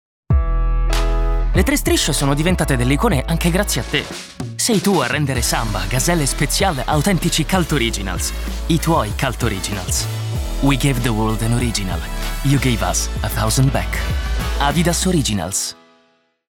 Urban Young